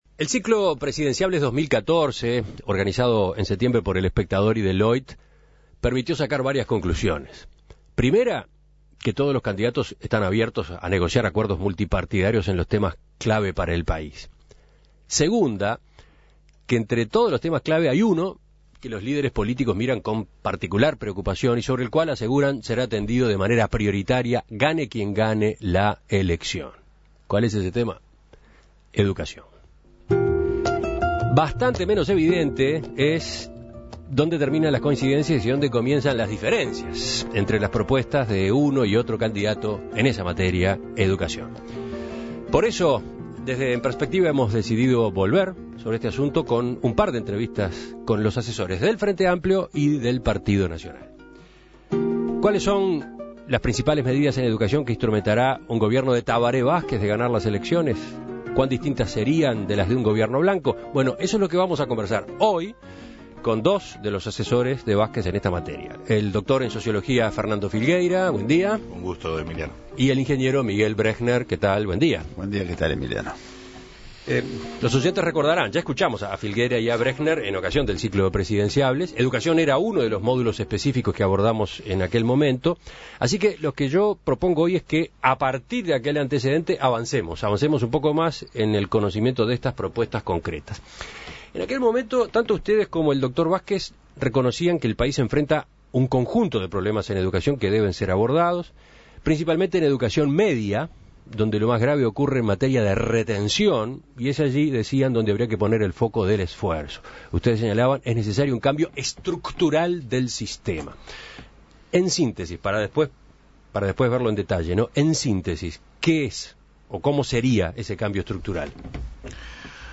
A más de una semana de las elecciones, En Perspectiva entrevista a los asesores de los partidos en esta materia.